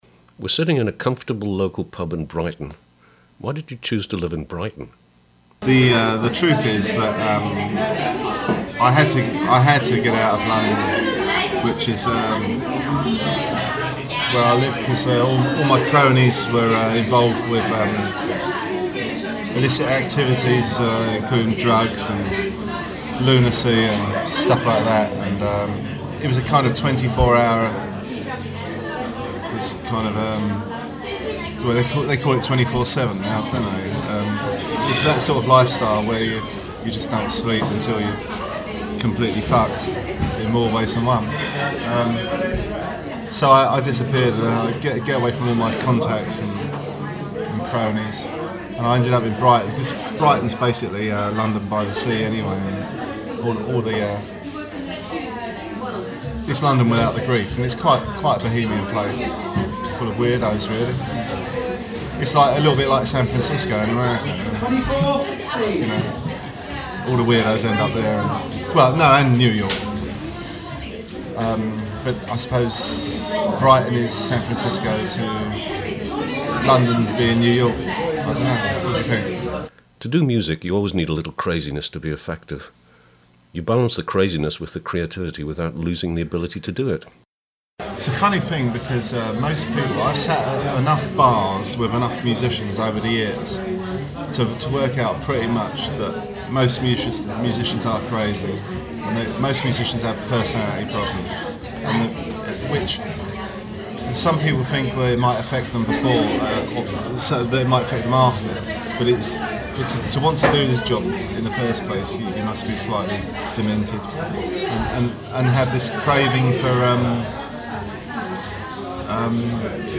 Captain Sensible In Interview